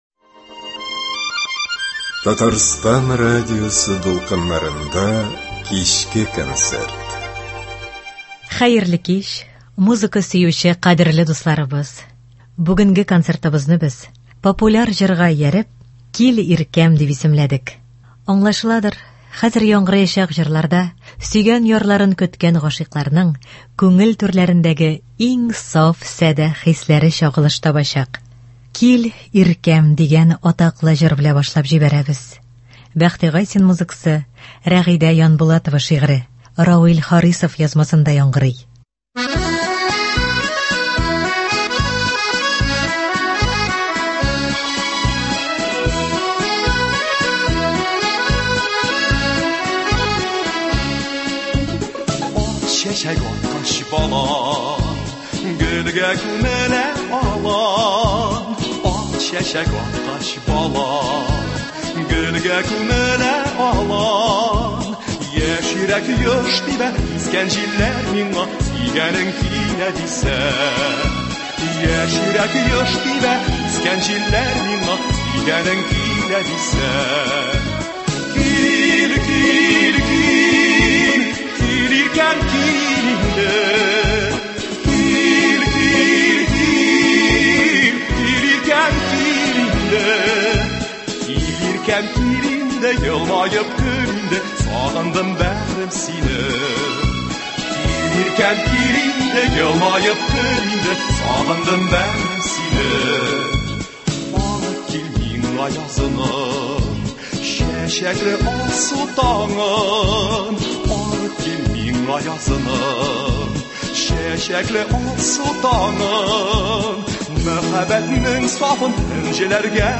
Лирик концерт.